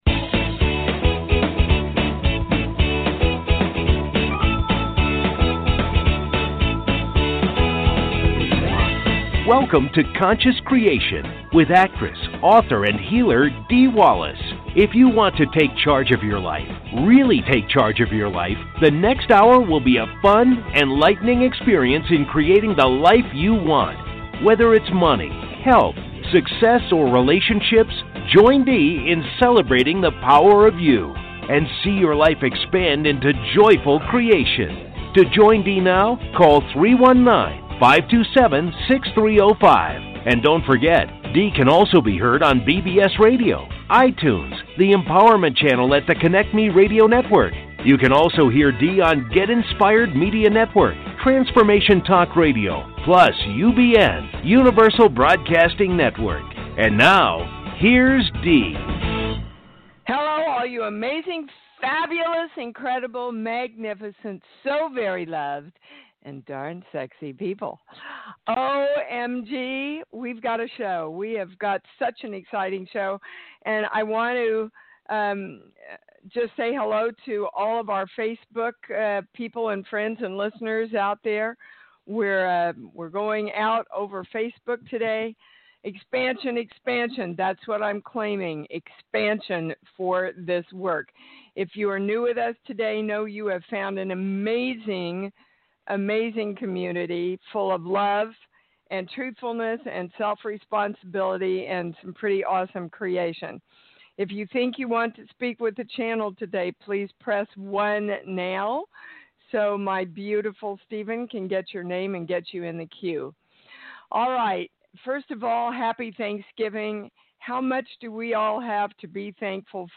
Conscious Creation Talk Show